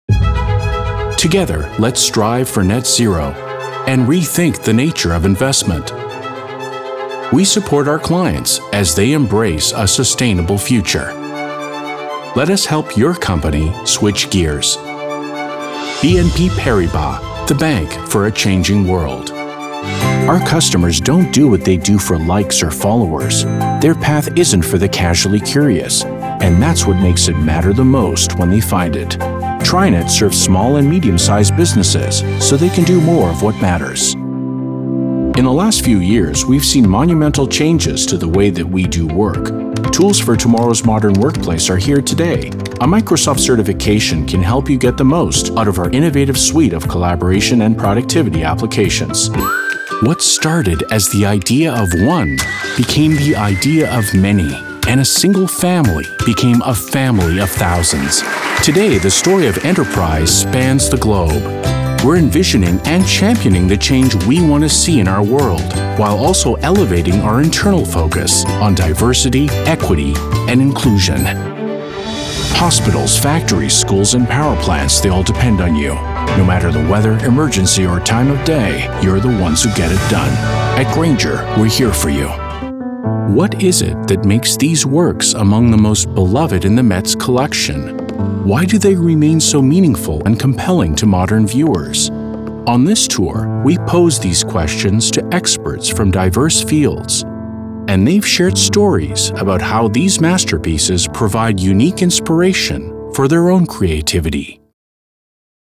Corporate/Industrial Narration Demo
Middle Aged
My broadcast quality studio includes an acoustically treated iso-booth and industry standard equipment including:
-Sennheiser MKH-416 mic